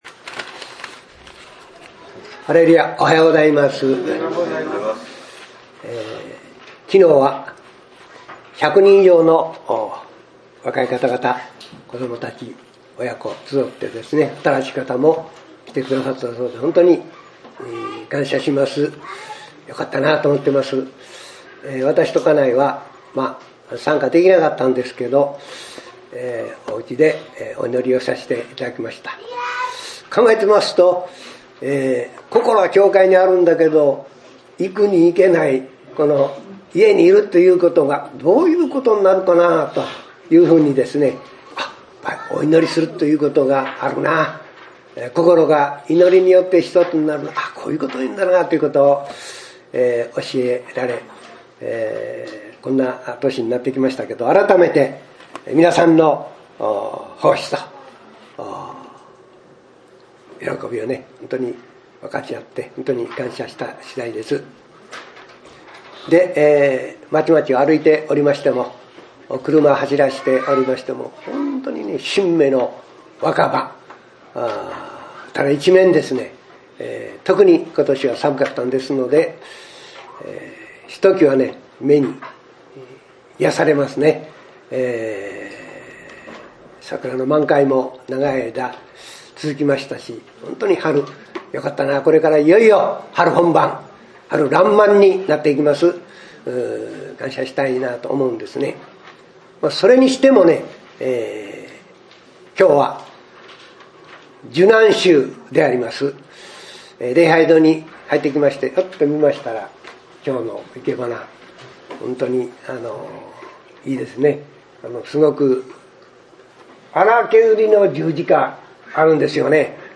今週の礼拝は受難週礼拝です。